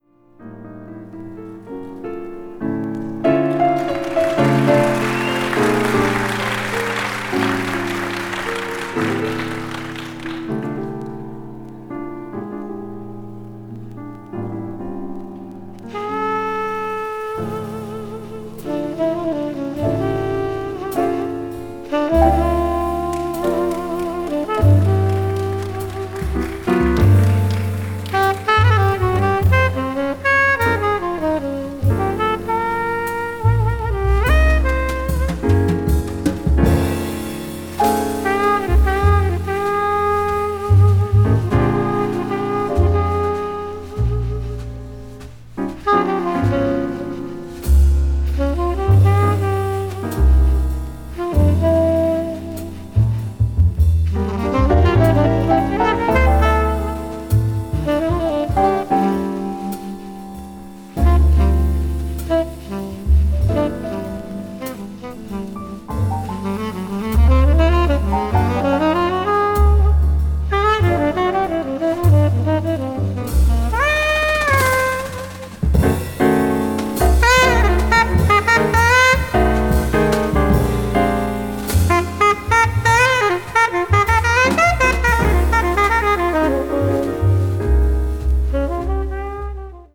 contemporary jazz   cool jazz   mood jazz